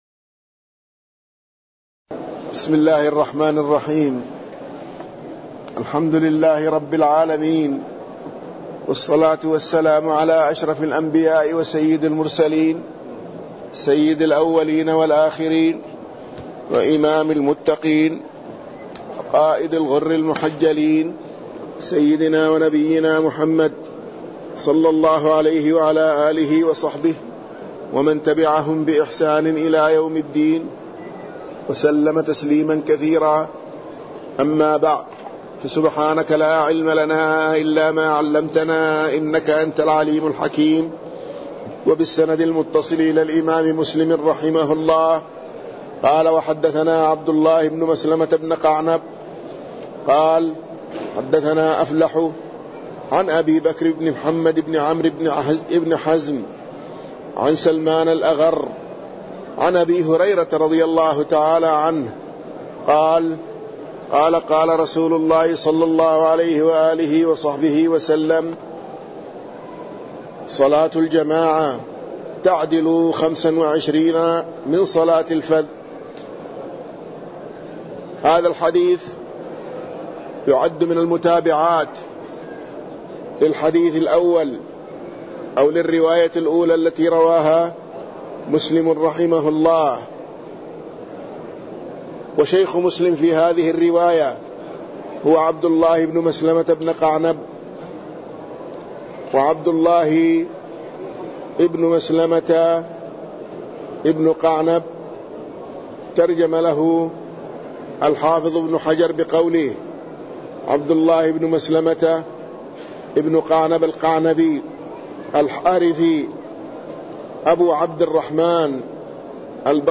تاريخ النشر ٢٩ صفر ١٤٣٠ هـ المكان: المسجد النبوي الشيخ